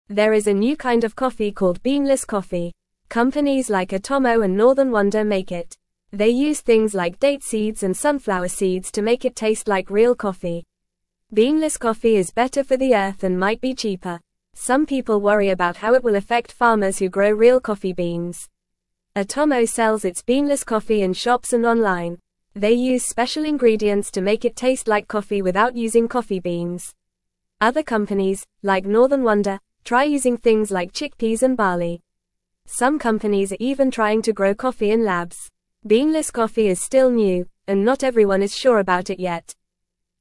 Fast
English-Newsroom-Beginner-FAST-Reading-Beanless-Coffee-A-New-Kind-of-Earth-Friendly-Brew.mp3